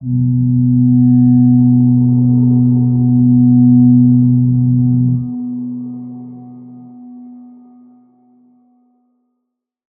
G_Crystal-B3-pp.wav